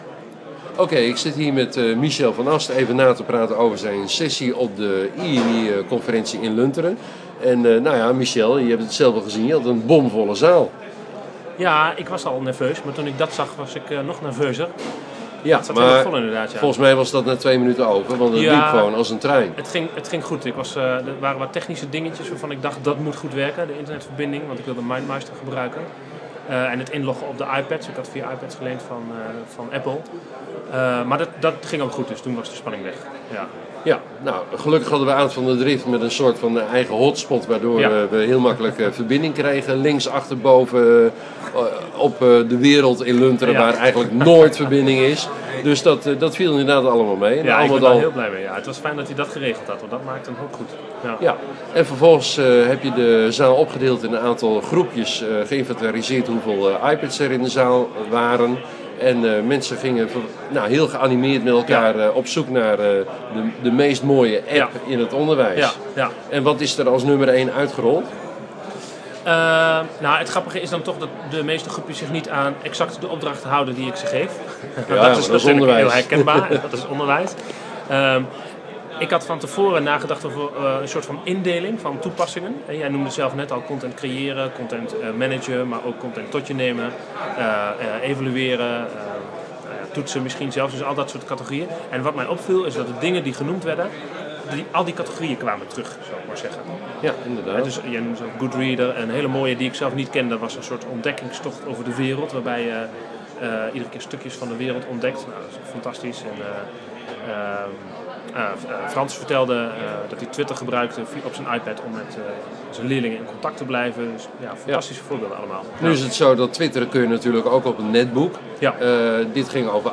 Share Facebook X Next Dit is dan de test via Audioboo zodat ik een goede vergelijking kan maken tussen de verschillende mogelijkheden.